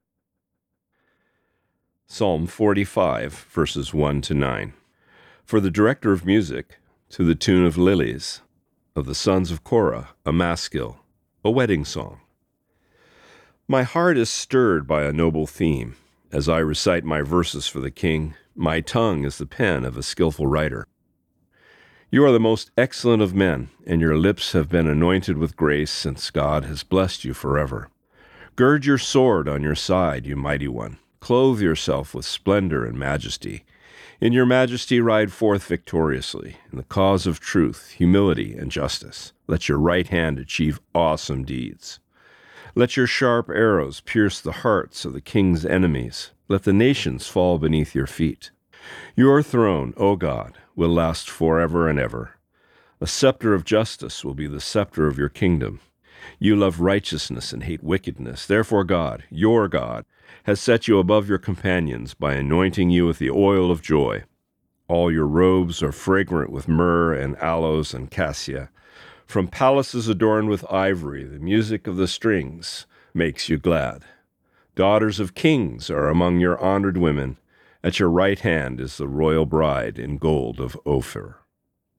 Reading: Psalm 45:1-9